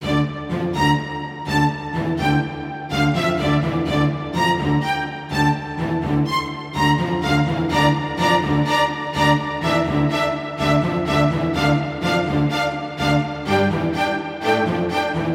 Tag: 125 bpm Cinematic Loops Strings Loops 2.58 MB wav Key : D